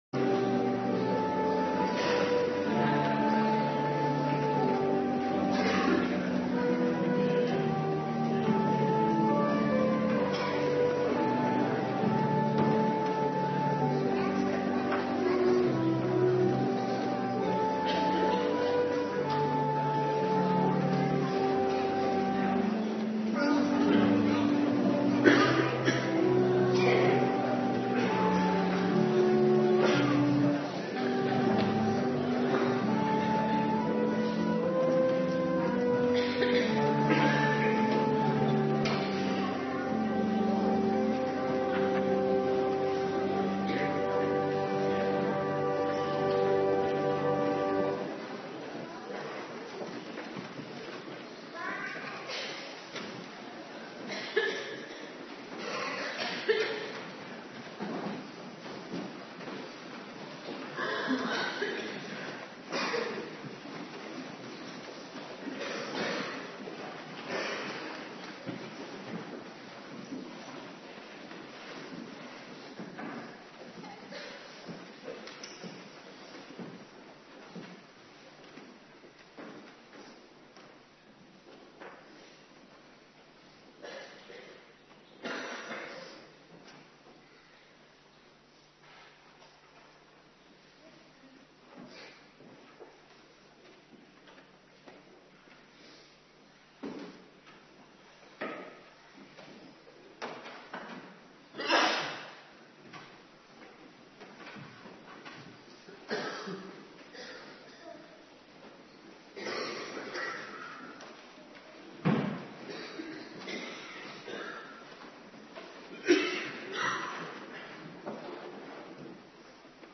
Morgendienst
09:30 t/m 11:00 Locatie: Hervormde Gemeente Waarder Agenda: Kerkdiensten Terugluisteren Spreuken 27:7